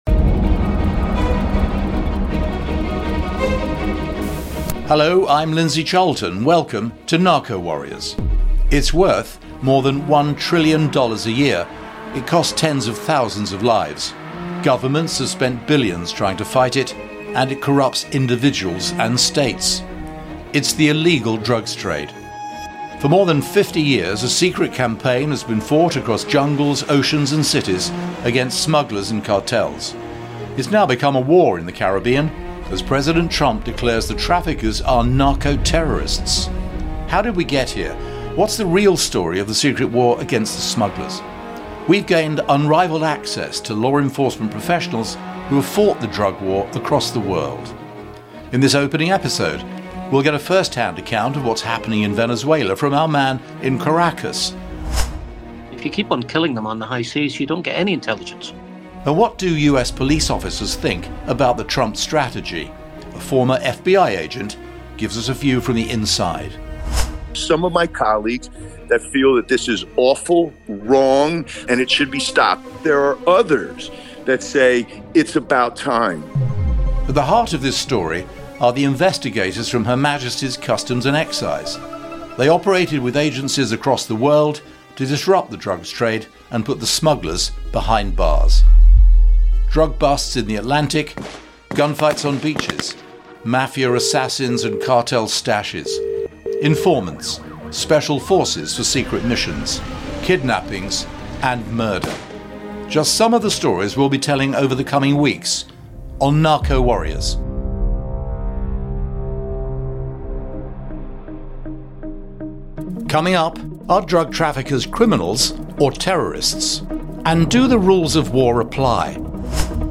In the studio, veteran investigators